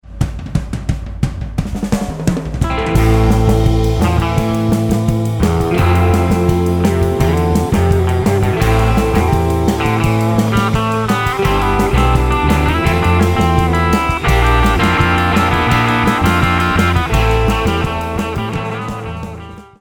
His specialty is guitar-oriented, vibey, 1960's-ish music.
A rockin' surf song with a '60s vibe.